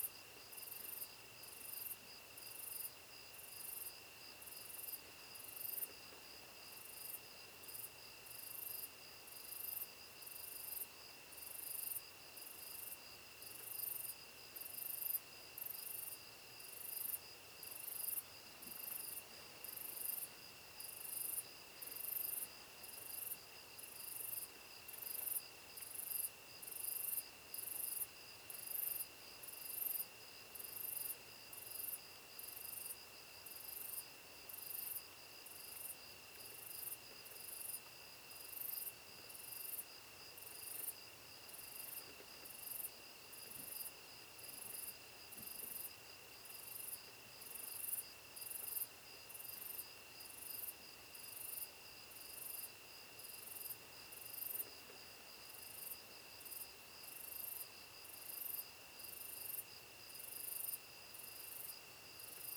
meadow_night.ogg